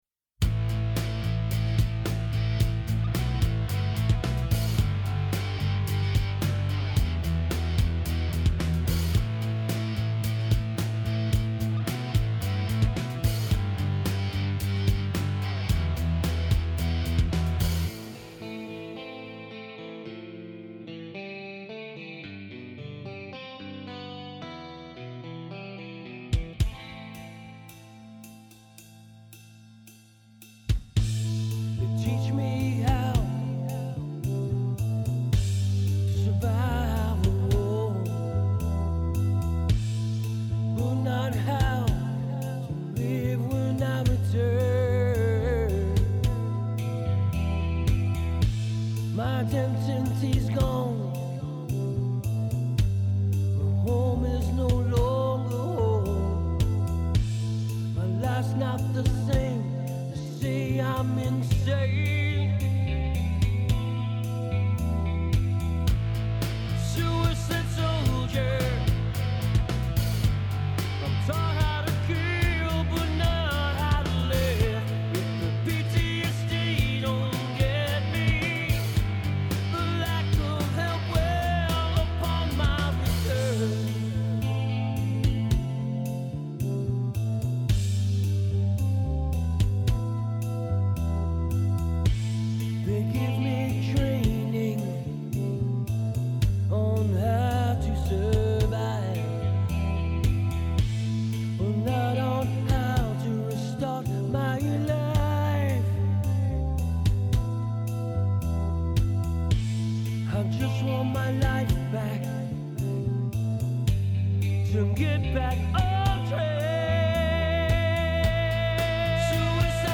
Vocals
Recorded At MWEMUSIC Little Birds Productions